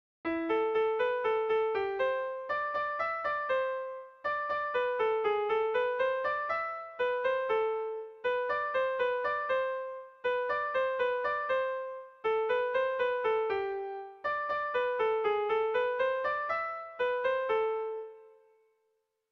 Tragikoa
Bederatzikoa, txikiaren moldekoa, 6 puntuz (hg) / Sei puntukoa, txikiaren moldekoa (ip)